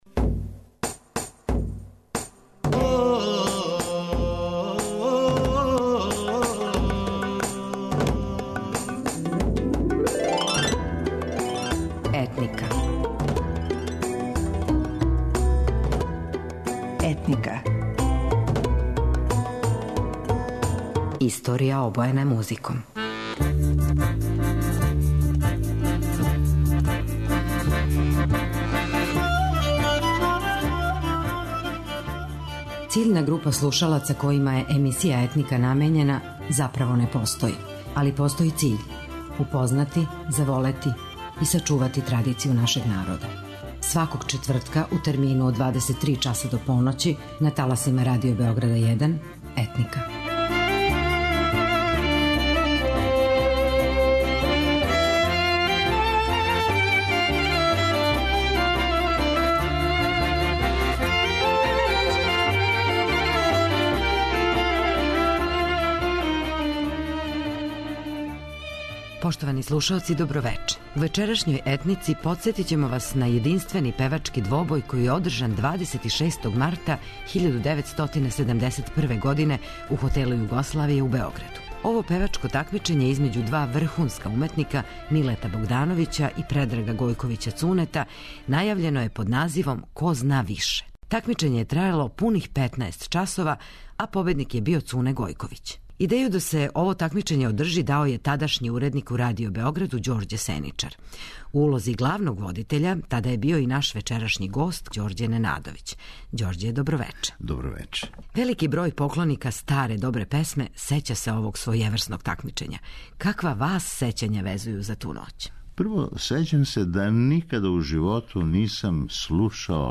У вечерашњој емисији емитоваћемо део снимка концерта 'Ко зна више', који је одржан 26. марта 1971. године у свечаној сали Хотела Југославија. Заправо, то није био класичан концерт, већ певачко такмичење два врхунска уметника - Милета Богдановића и Предрага Гојковића Цунета.